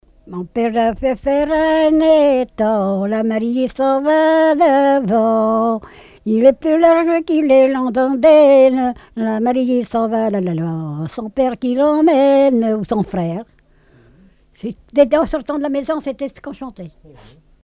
Mémoires et Patrimoines vivants - RaddO est une base de données d'archives iconographiques et sonores.
Genre laisse
Répertoire de chants brefs et traditionnels
Pièce musicale inédite